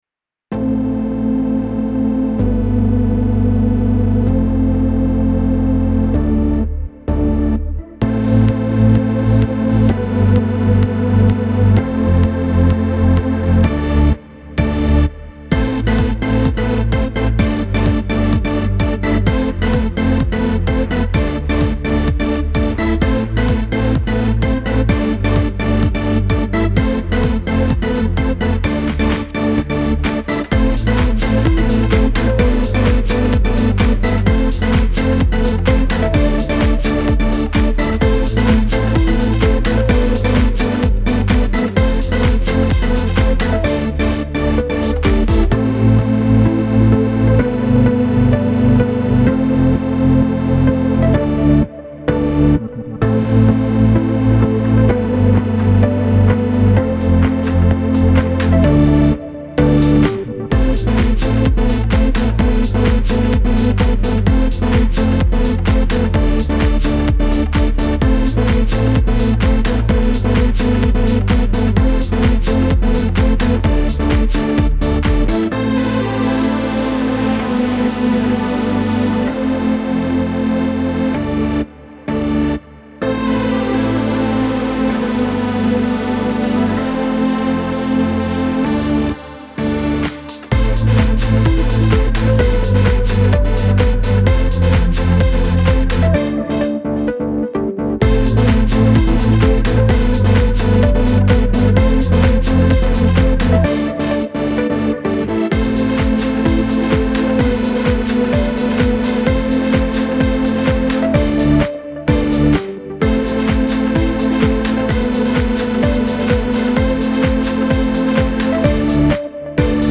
Genre: Dance, Soundtrack.
positive
electro
ambient
instrumental
expressive
thoughtful
organ
strings
synths
motivating
rhythmic
progressive
growing